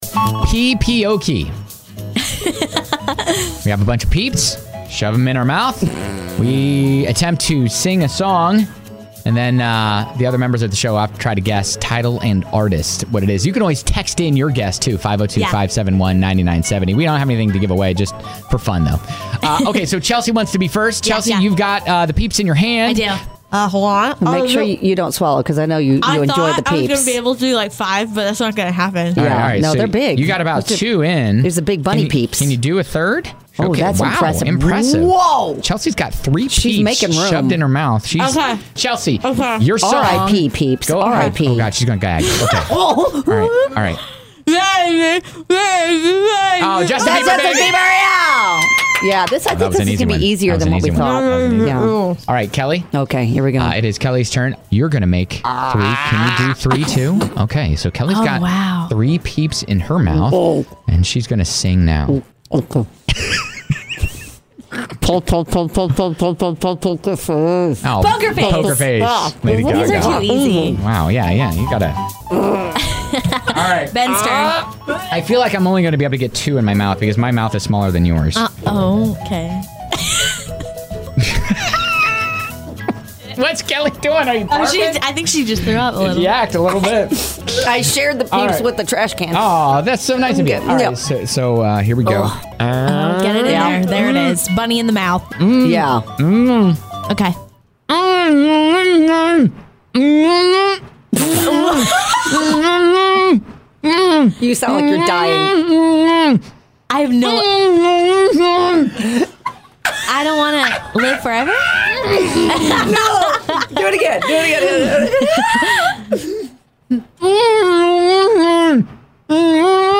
We just tried to shove some peeps in our mouths and sing some songs...it went horribly wrong.